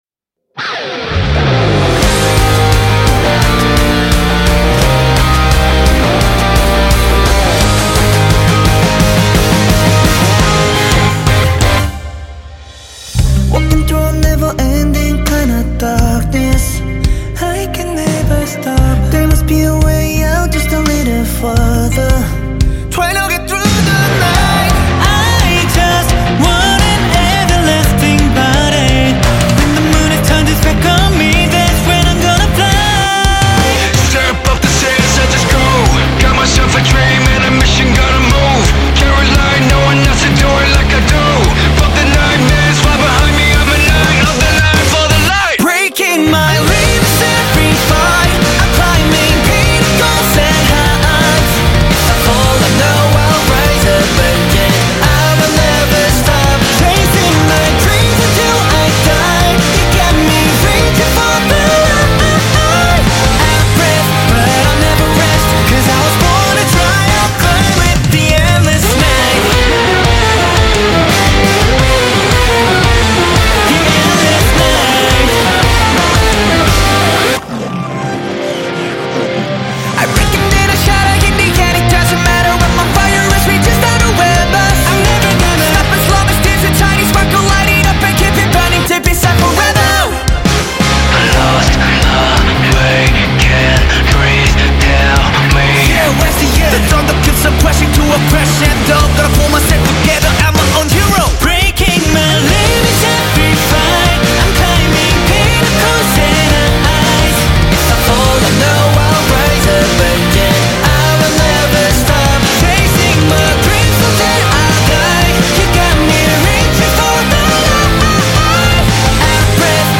Label Dance